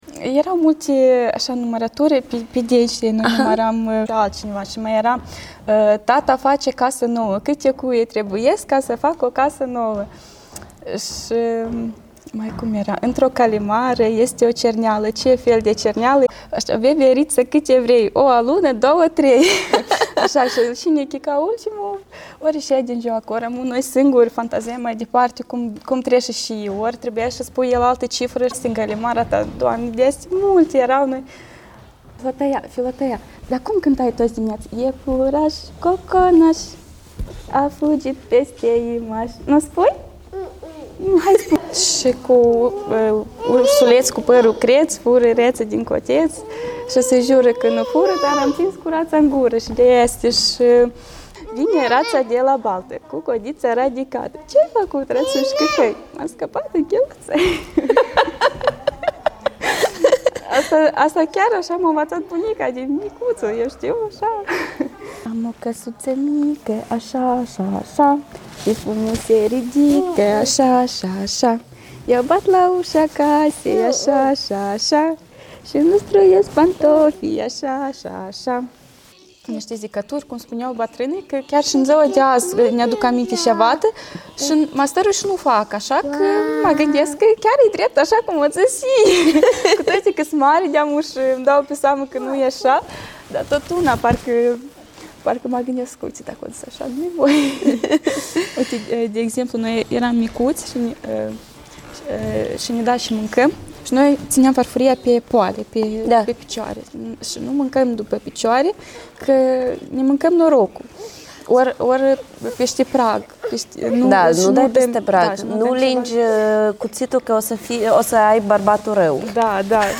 Poezii